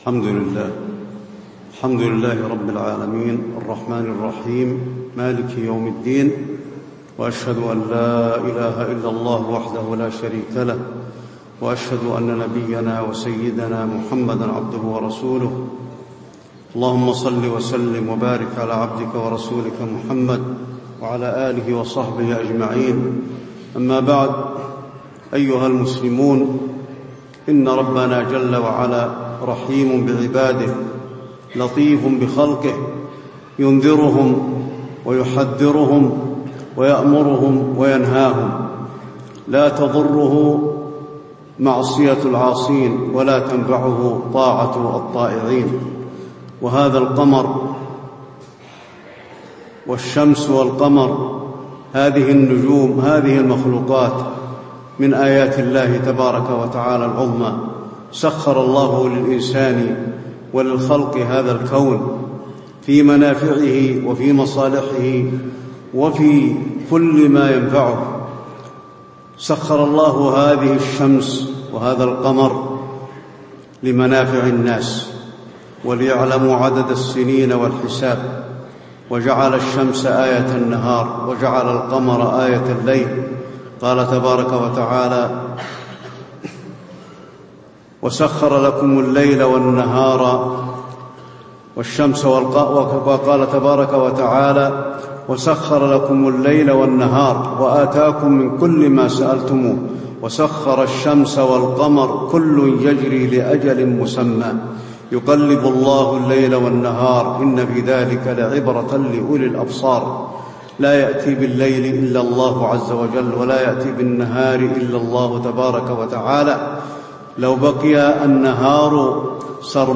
خطبة الخسوف المدينة - الشيخ علي الحذيفي
تاريخ النشر ١٤ ذو الحجة ١٤٣٦ هـ المكان: المسجد النبوي الشيخ: فضيلة الشيخ د. علي بن عبدالرحمن الحذيفي فضيلة الشيخ د. علي بن عبدالرحمن الحذيفي خطبة الخسوف المدينة - الشيخ علي الحذيفي